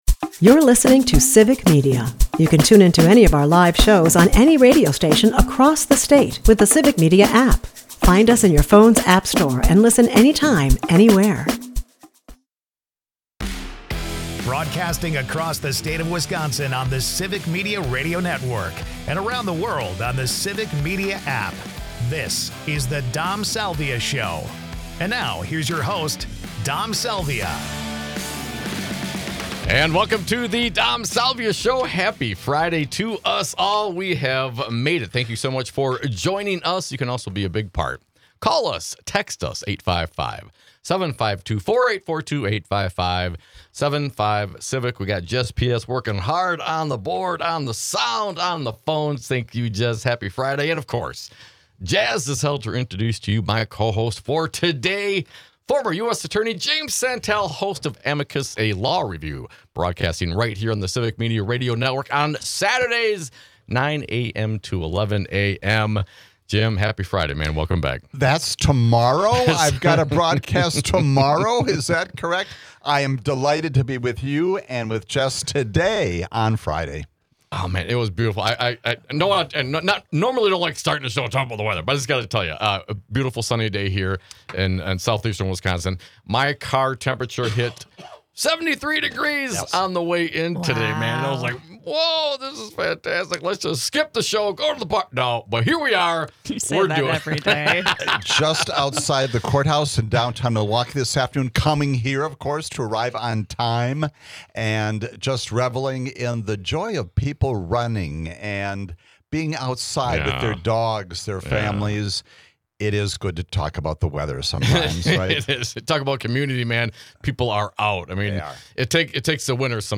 joins us as cohost for the day.